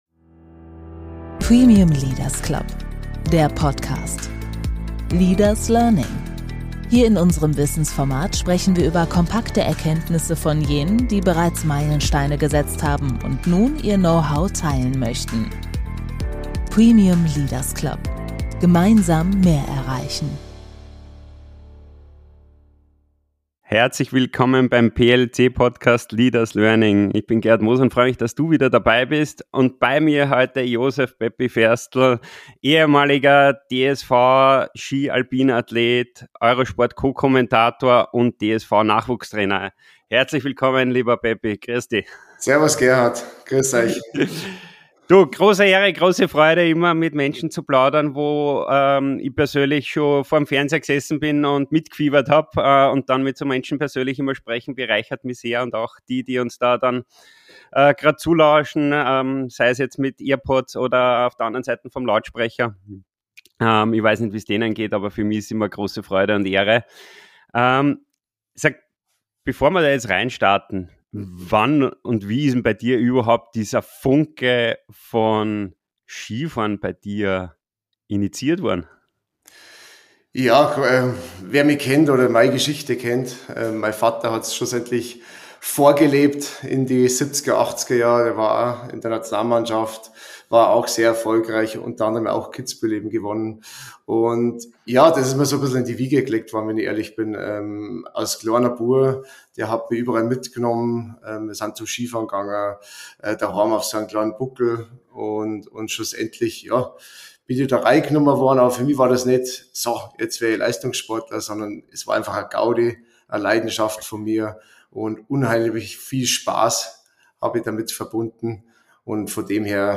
Im Gespräch mit Josef Ferstl | Vom Hahnenkamm zum Neuanfang: „Pepi“ Ferstl über Druck, Tiefschläge und innere Klarheit ~ DER PODCAST Podcast